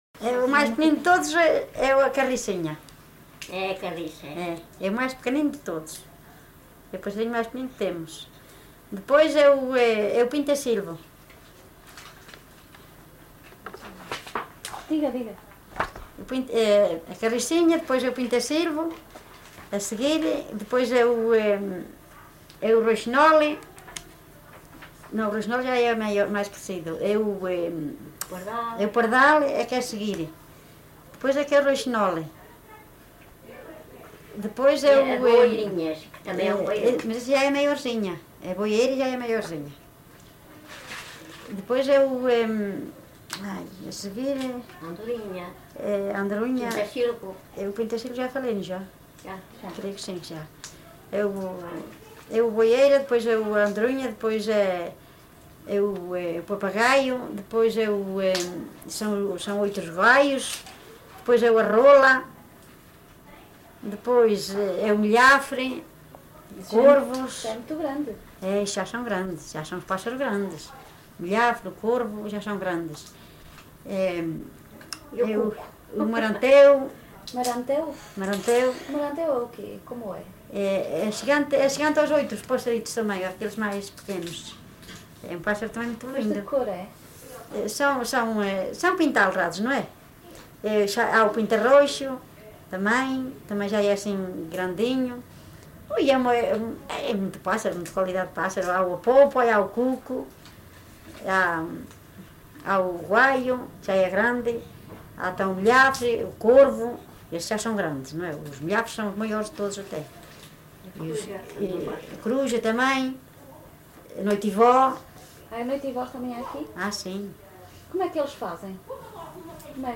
LocalidadeGranjal (Sernancelhe, Viseu)